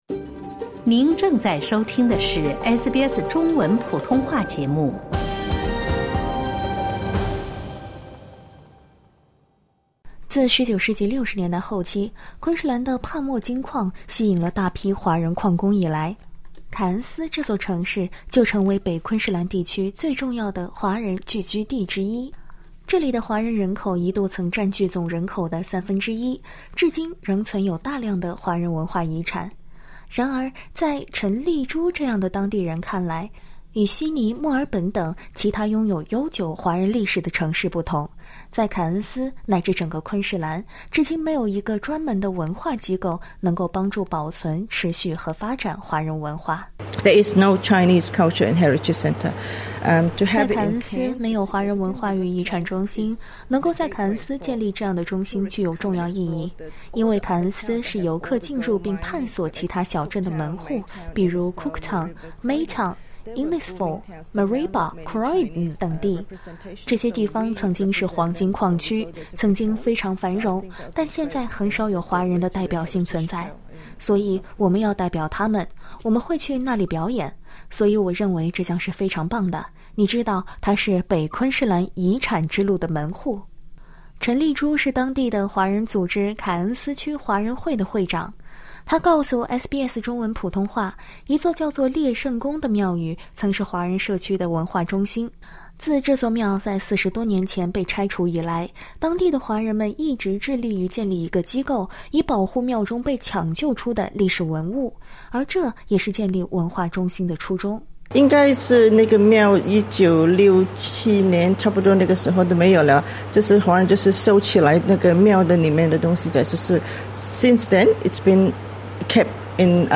请听采访： LISTEN TO “几代华人的梦想”：凯恩斯拟建昆州首个华人遗产与文化中心 SBS Chinese 08:46 cmn 欢迎下载应用程序SBS Audio，订阅Mandarin。